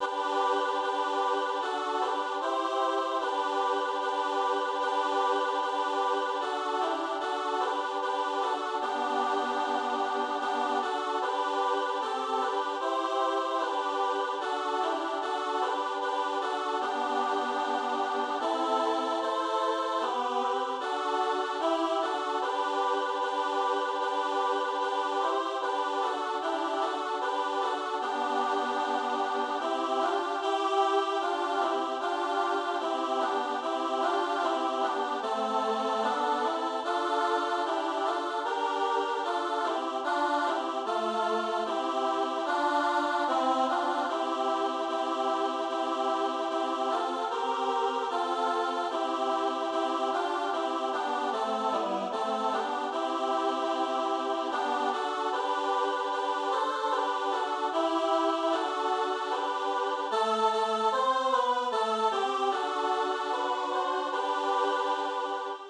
для женского трио